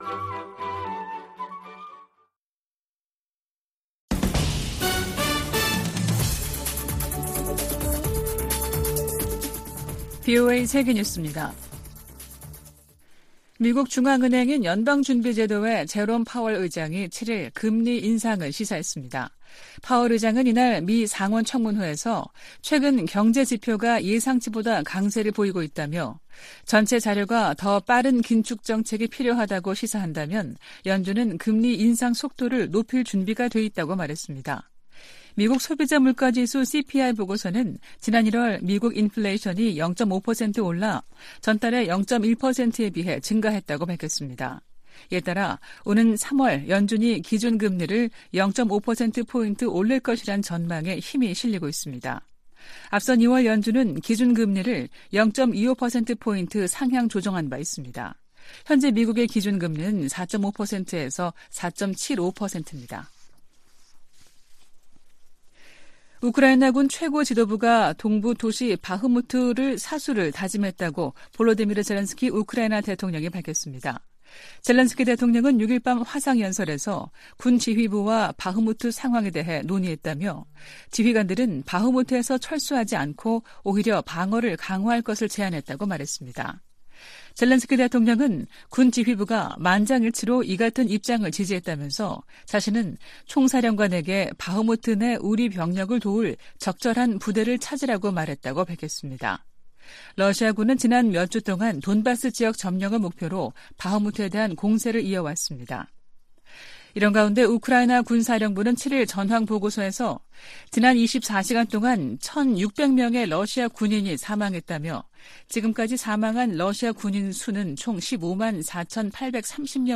VOA 한국어 아침 뉴스 프로그램 '워싱턴 뉴스 광장' 2023년 3월 8일 방송입니다. 북한이 이달 또는 다음달 신형 고체 대륙간탄도미사일(ICBM)이나 정찰위성을 발사할 가능성이 있다고 한국 국가정보원이 전망했습니다. 미 국무부는 강제징용 문제 해법에 대한 한일 간 합의를 환영한다는 입장을 밝혔습니다. 한국이 역사 문제 해법을 발표한 데 대해 일본도 수출규제 해제 등으로 적극 화답해야 한다고 미국 전문가들이 주문했습니다.